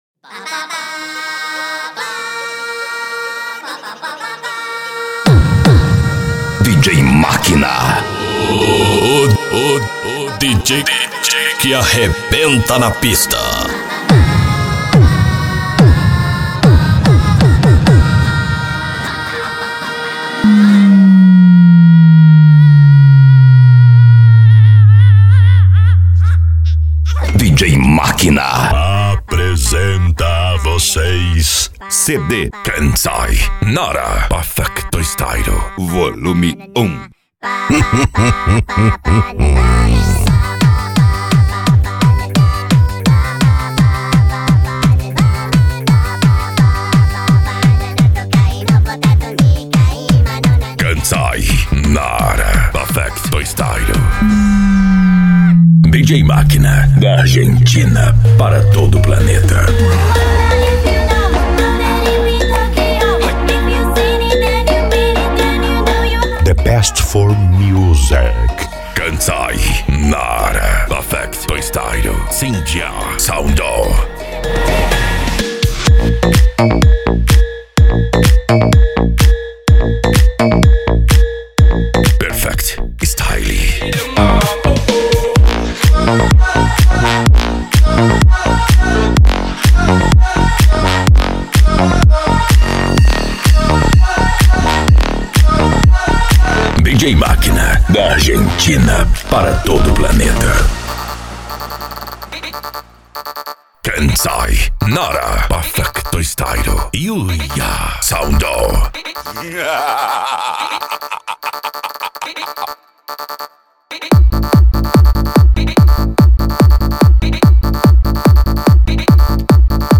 Deep House
Eletronica
Hard Style
PANCADÃO